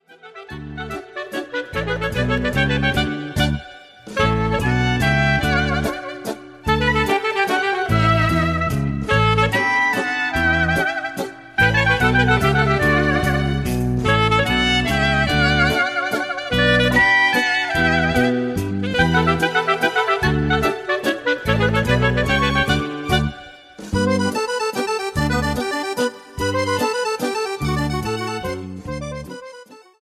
MAZURCA  (03:14)